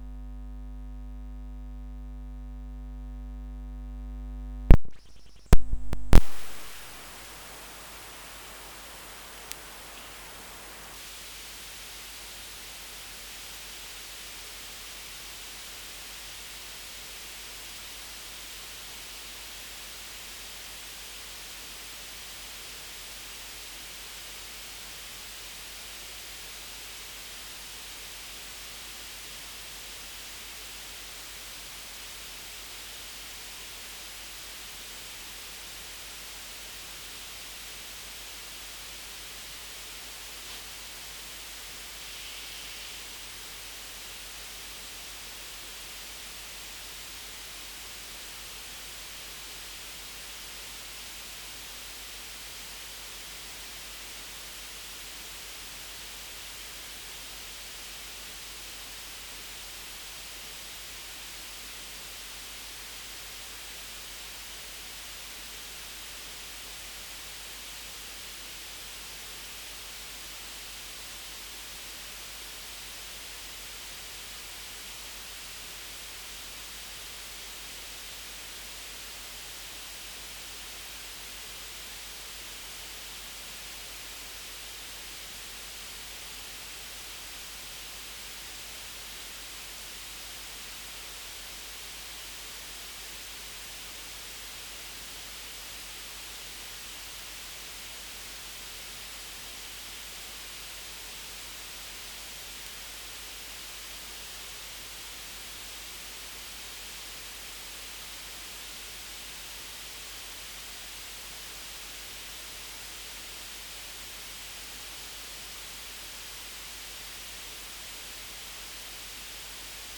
This Below is an ultrasound recording of a living space:
This above are the actual real-time frequencies of ultrasound in an enclosed space recorded by an ultrasound microphone. They maximise around 27 Khz but go up to above 50 Khz and below 200 Khz.
An ultrasound microphone is designed to reduce these frequencies so we can hear what they sound like.
Notice the chit chat in the recording which you would not hear with normal hearing because of its high frequency.
ultrasound-realtime-recording-8-sept-2019.wav